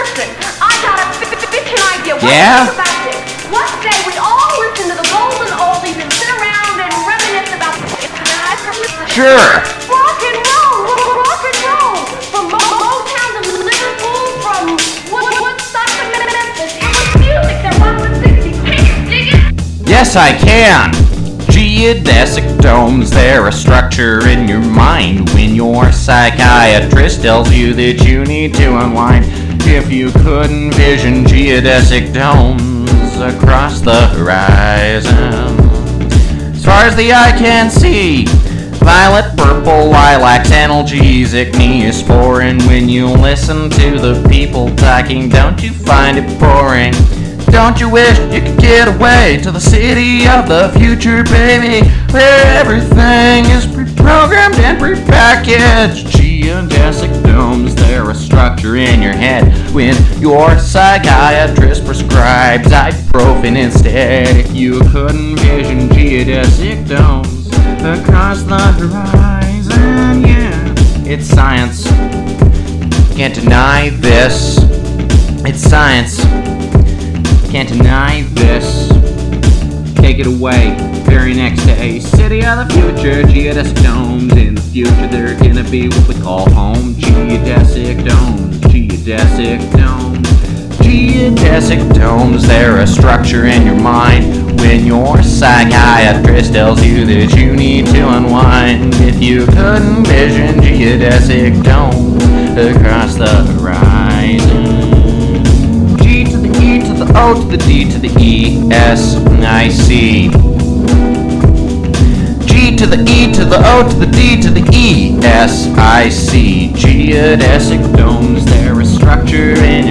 Denver New Wave, Hip-Hop, Plunderphonics,